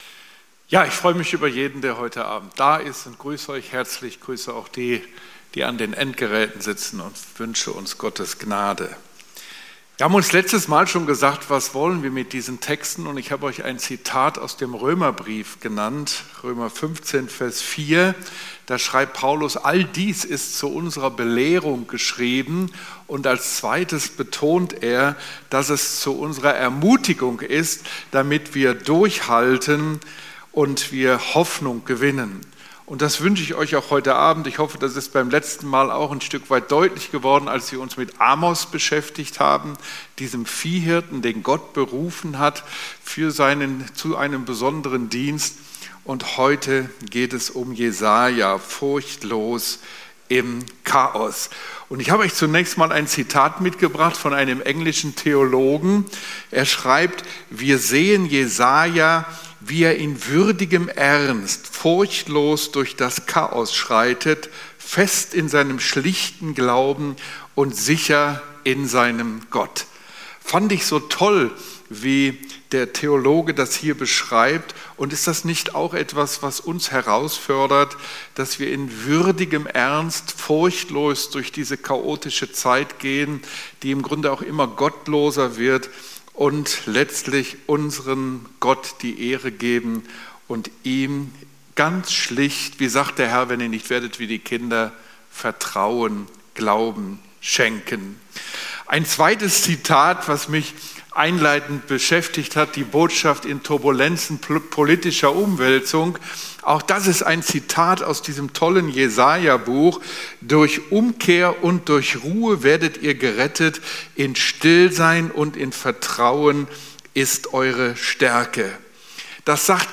30.07.2025 Themanabend 2 ~ Predigten - FeG Steinbach Podcast